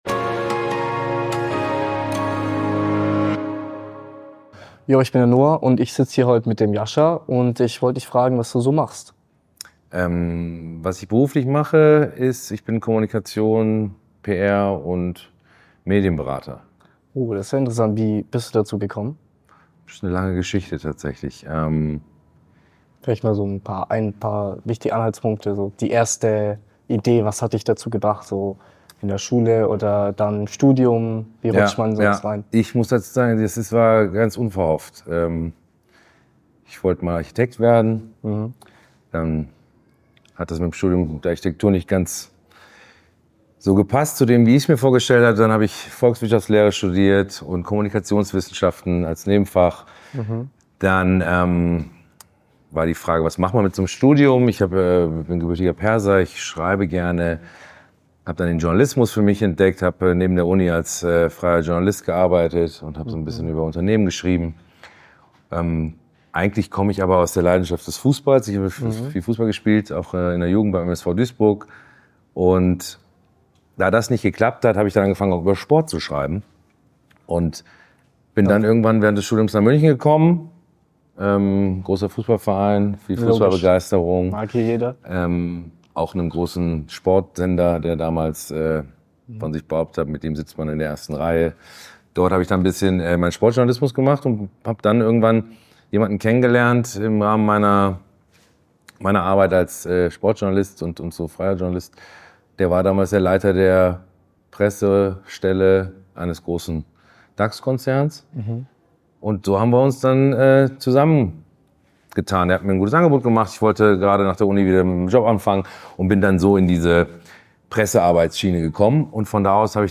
KÖNIGSKLASSE - Die Gen Z interviewt CXOs über ihr Leben